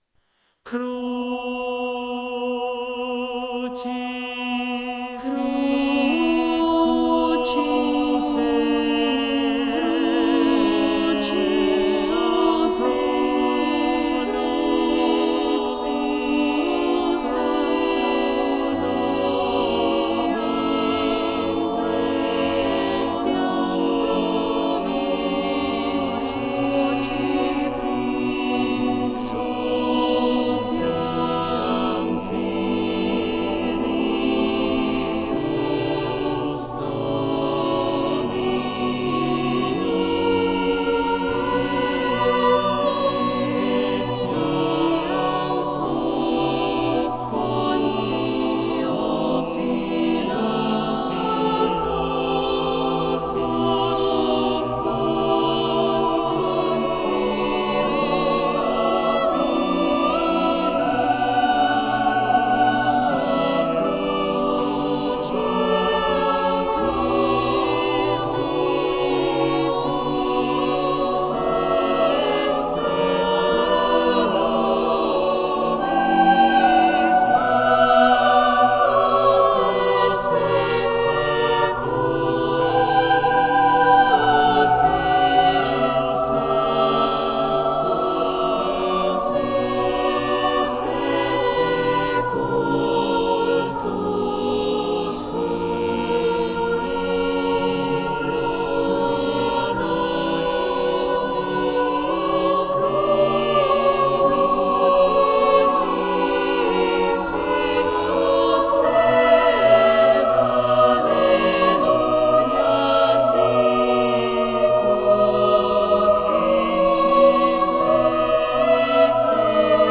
A religious motet I began writing this afternoon and have finished composing in under five hours.